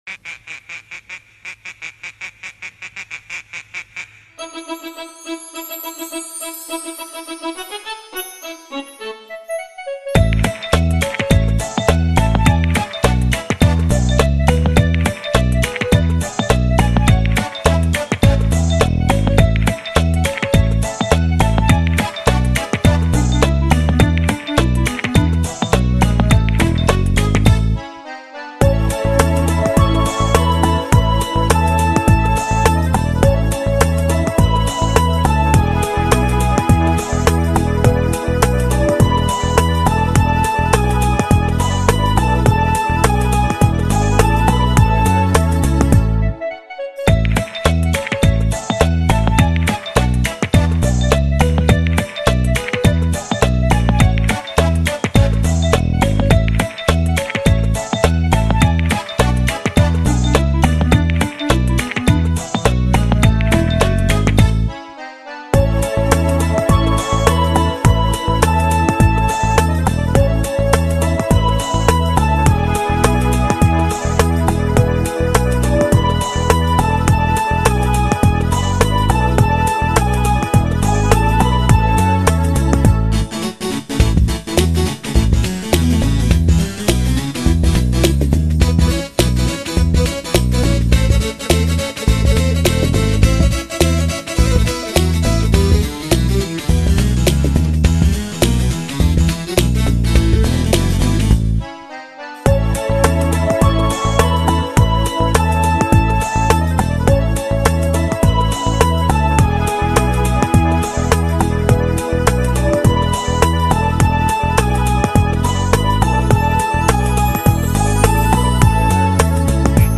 Музыка для танцев утят без слов